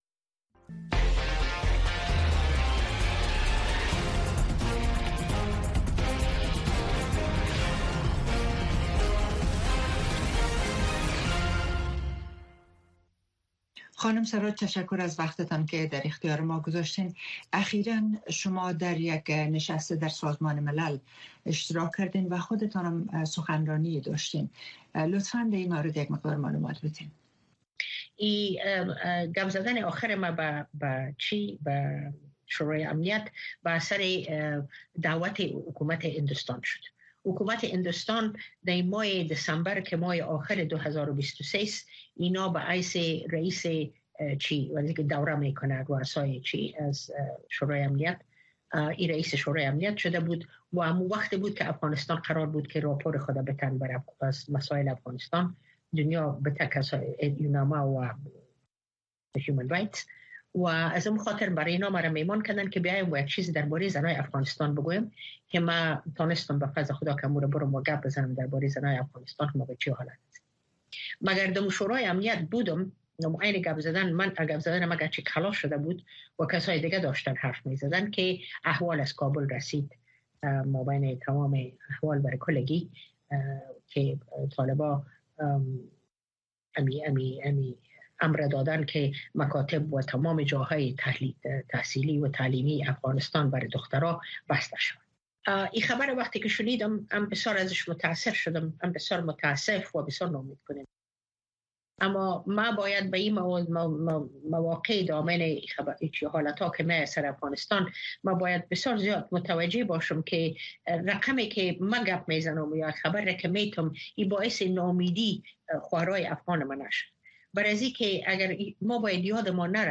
گفتگو‌های ویژه با مسوولان، مقام‌ها، کارشناسان و تحلیلگران در مورد مسایل داغ افغانستان و جهان را هر شنبه در نشرات ماهواره‌ای و دیجیتلی صدای امریکا دنبال کنید.